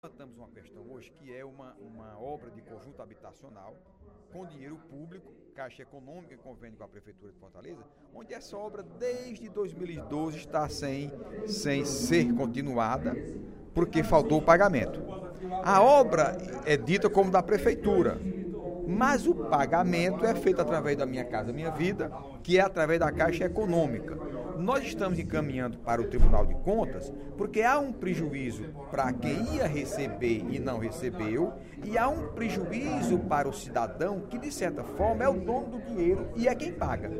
O deputado Heitor Férrer (PDT) denunciou, nesta quarta-feira (02/09), durante o primeiro expediente da sessão plenária, a paralisação das obras de um conjunto habitacional na Vila do Mar III, por falta de pagamento da Prefeitura de Fortaleza.